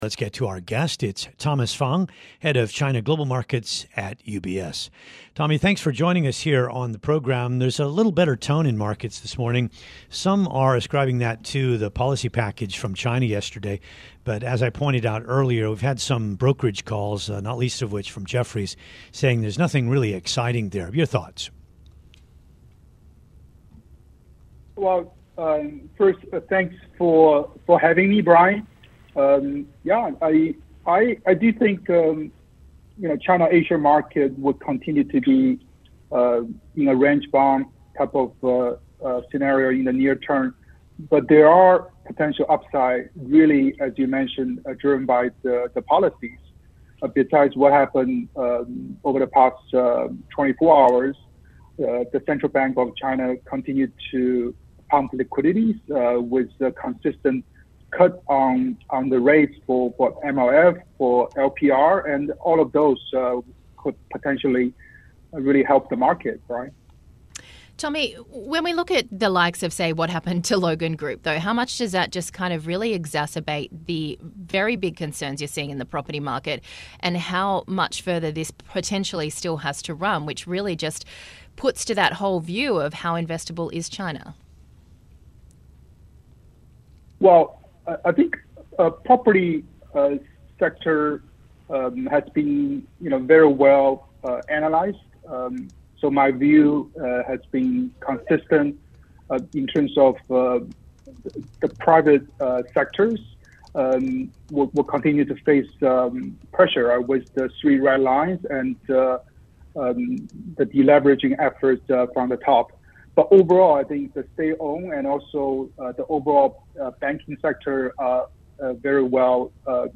(Radio) - Bloomberg Daybreak: Asia Edition